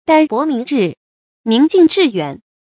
dàn bó míng zhì, níng jìng zhì yuǎn
澹泊明志，宁静致远发音